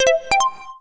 Index of /phonetones/unzipped/Samsung/SGH-i310/Camera Sounds
camcorder_start_musical01.wav